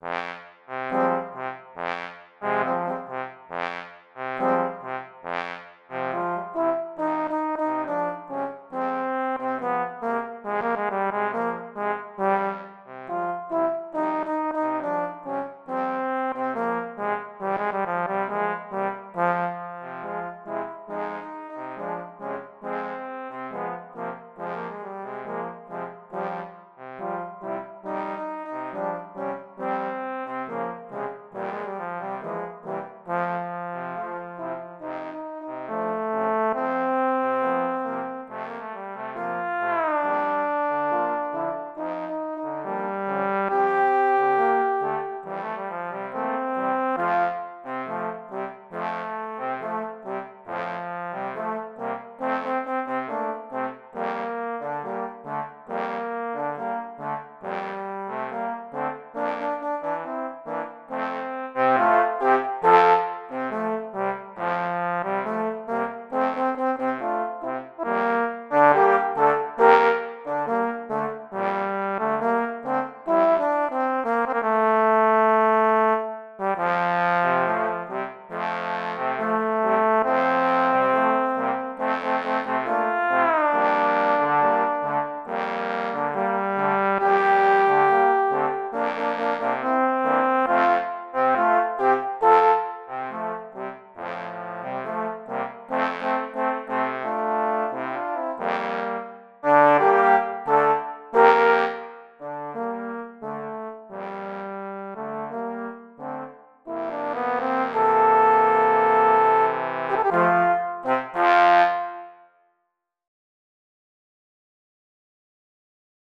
Voicing: Trombone Quartet